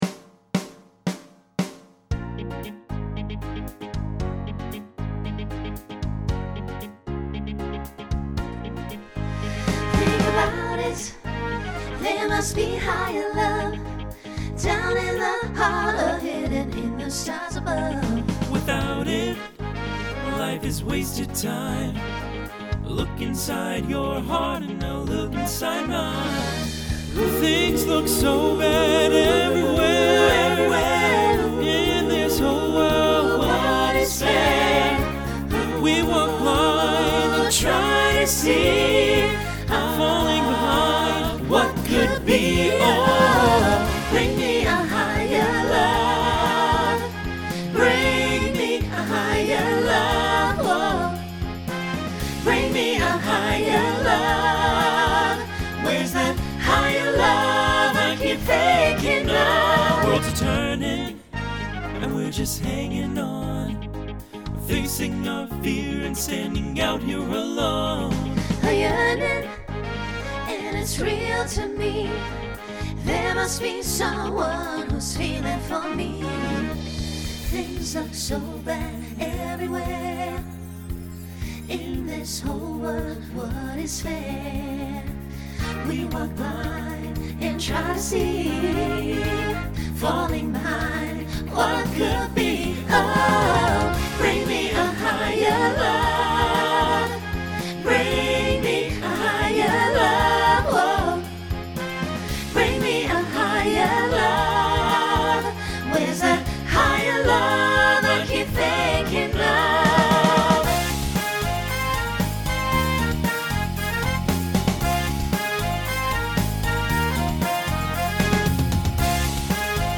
Genre Pop/Dance
Transition Voicing SATB